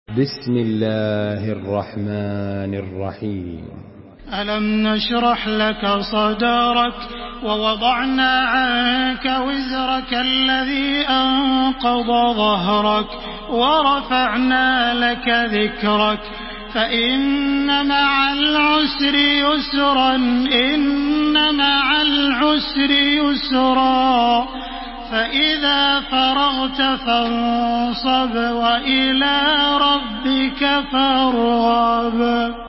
تحميل سورة الشرح بصوت تراويح الحرم المكي 1433
مرتل